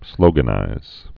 (slōgə-nīz)